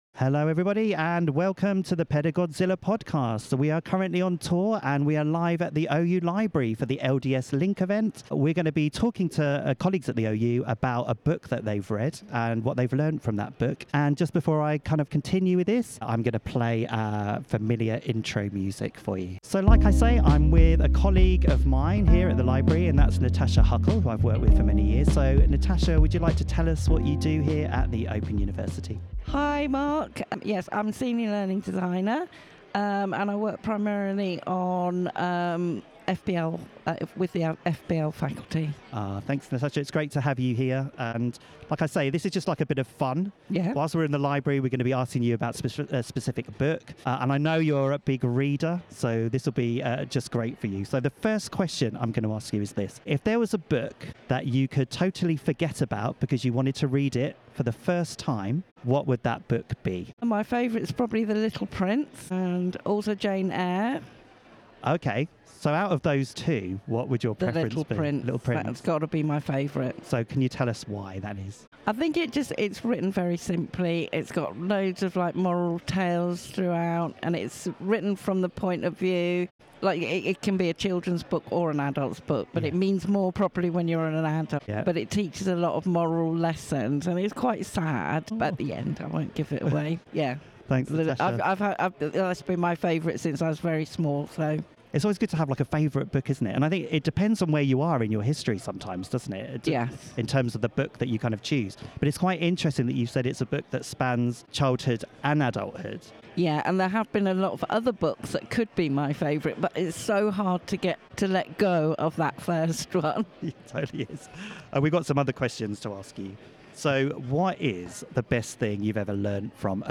More from our miniseries at the LDS Link Event in October 2024.